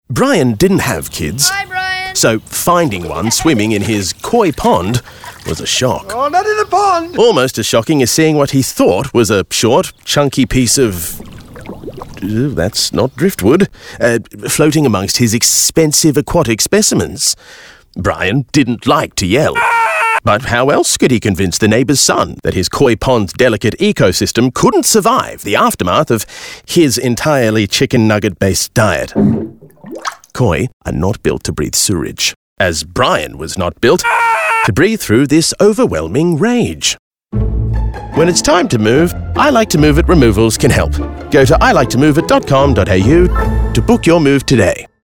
2024-When-its-time-to-move-Koi-Broadcast-Audio-MP3.mp3